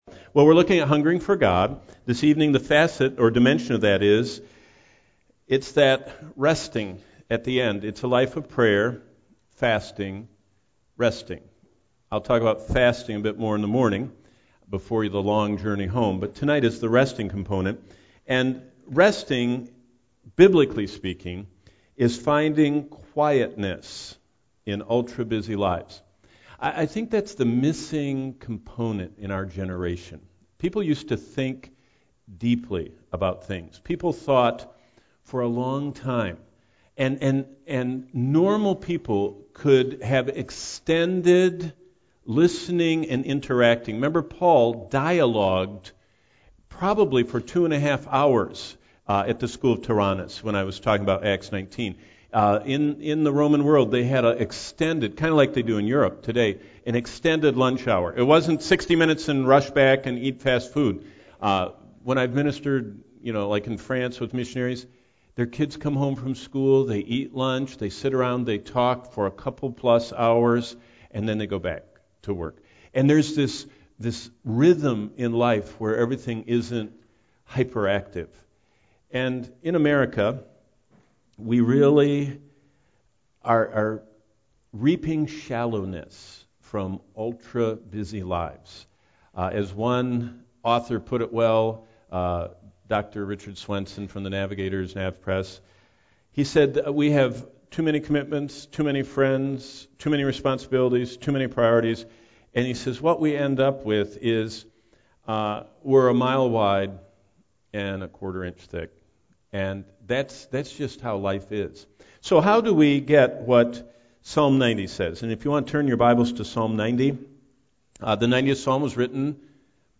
Slides Check Out All The Sermons In The Series You can find all the sermons and short clips from this series